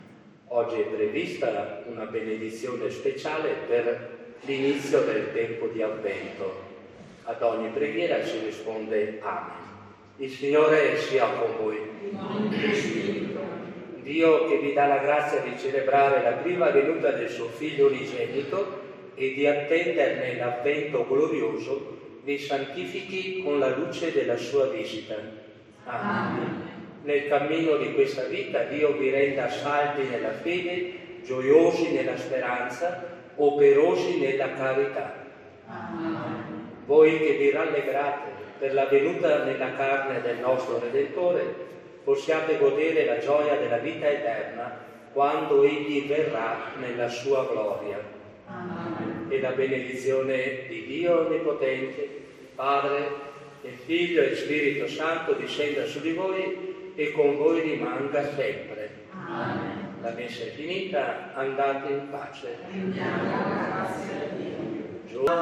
benedizione-inizio-Avvento-2023.mp3